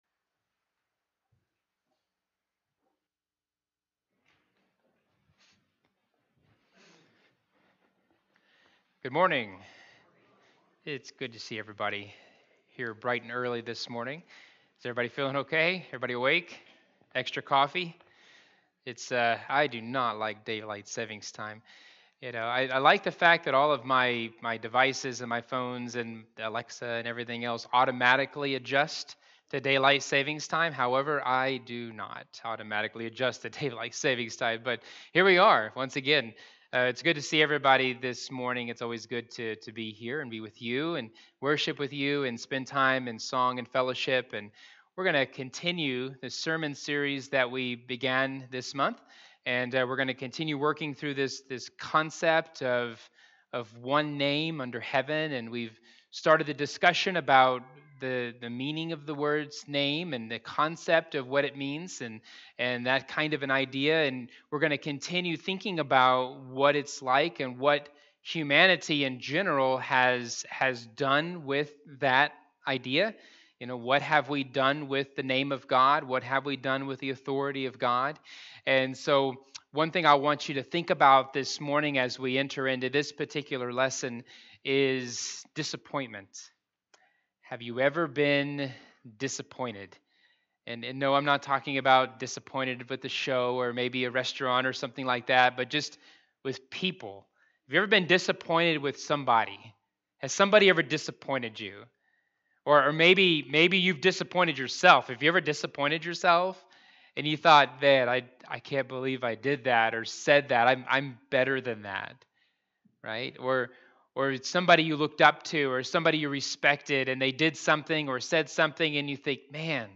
All Sermons One Name Under Heaven